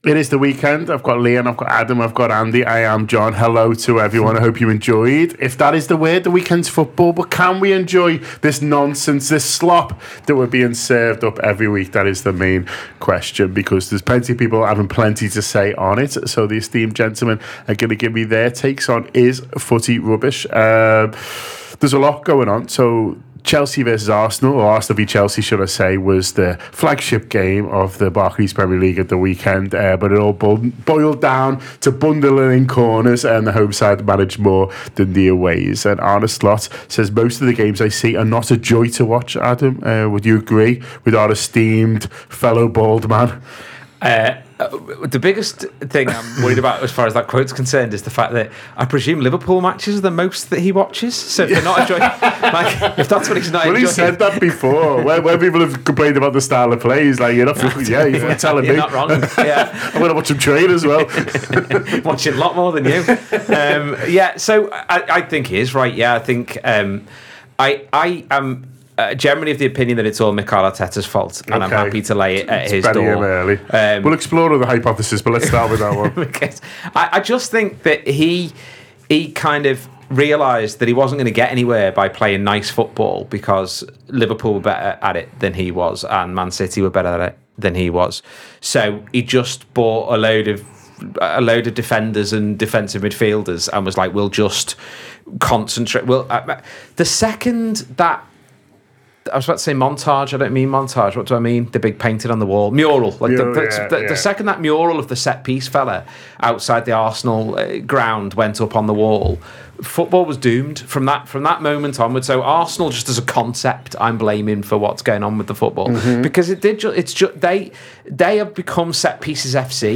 Below is a clip from the show – subscribe for more on the Premier League